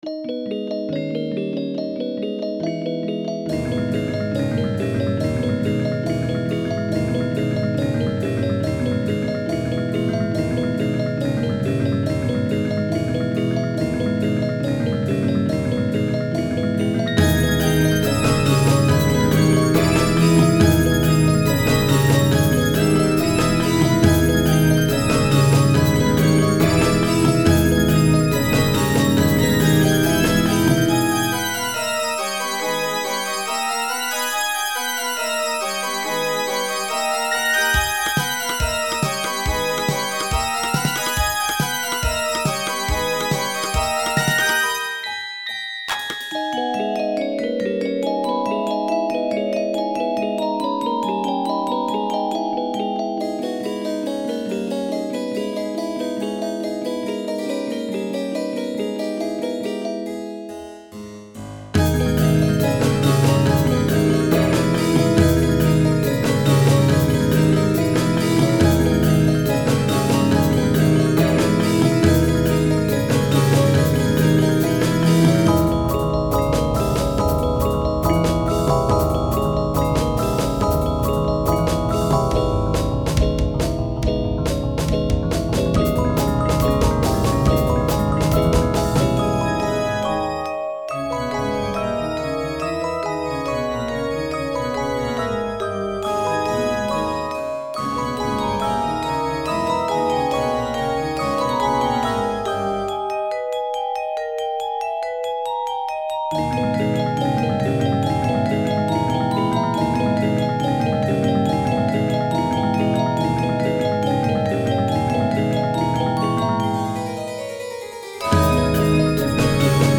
A hollow theme for Halloween (Fall 2025 competition) - Electronic - Young Composers Music Forum